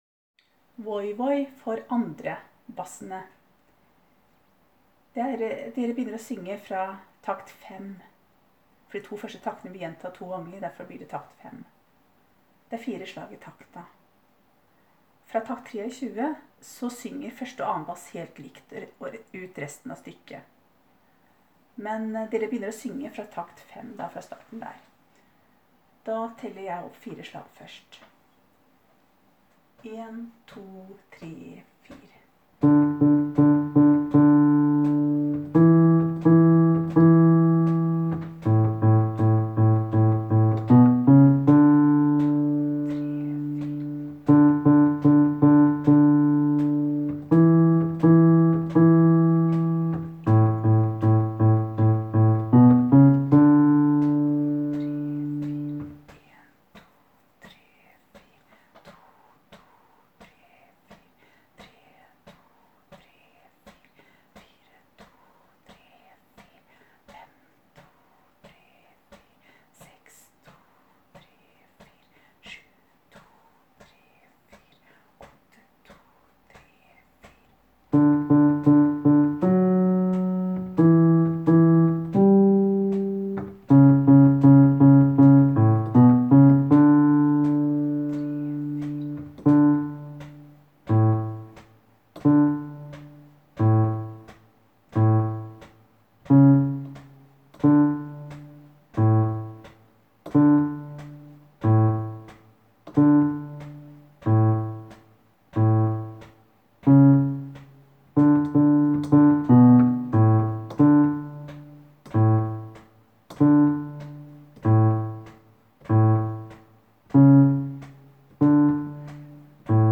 Voi-Voi-2-Basser.m4a